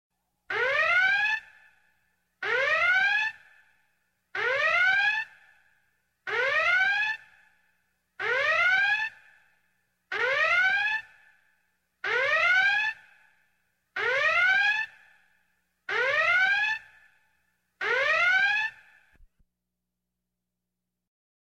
• Качество: 128, Stereo
тревога
панк